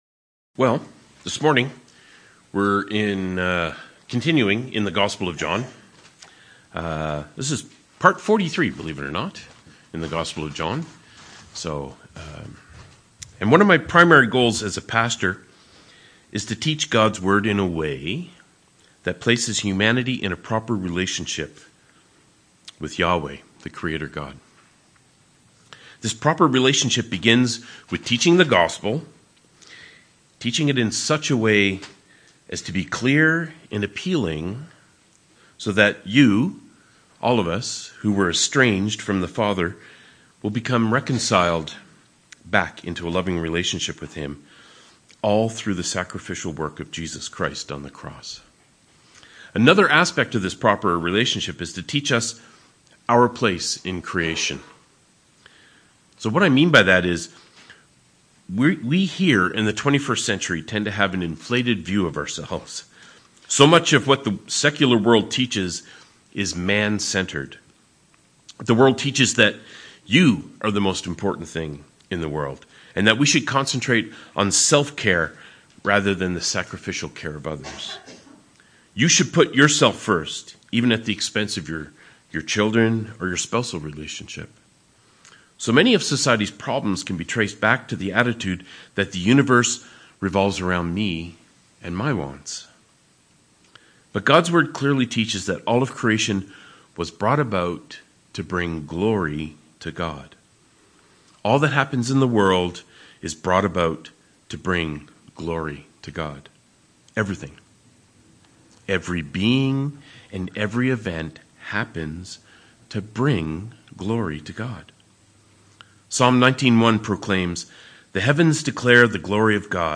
Passage: John 11: 1-16 Service Type: Sermons